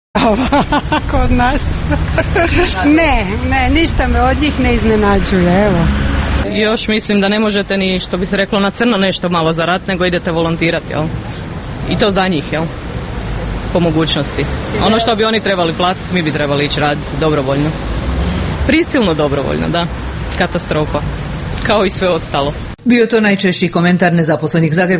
Anketa ispred Zavoda za zapošljavanje